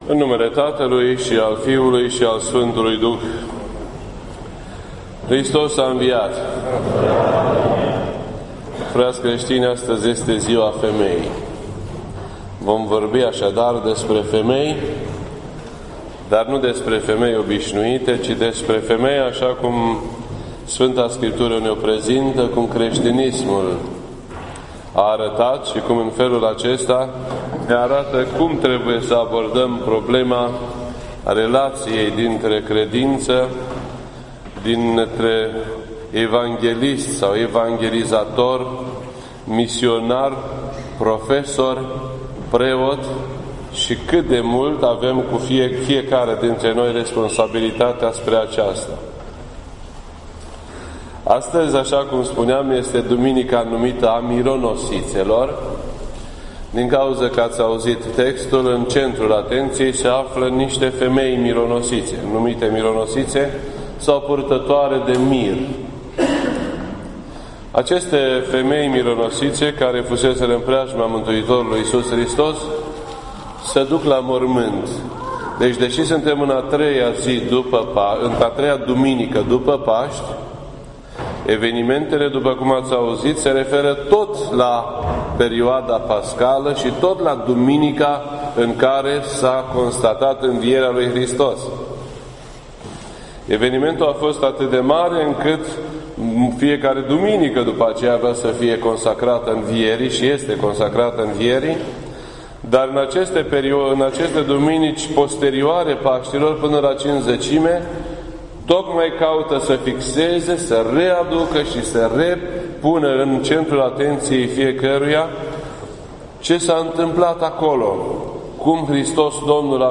This entry was posted on Sunday, May 15th, 2016 at 10:43 AM and is filed under Predici ortodoxe in format audio.